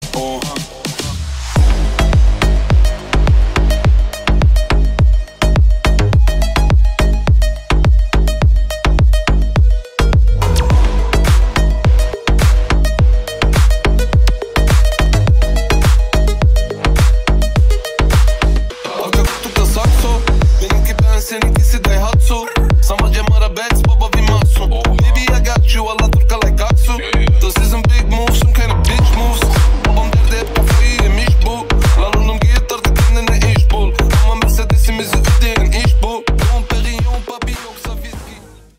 • Качество: 320, Stereo
громкие
мощные
басы
Brazilian bass
качающие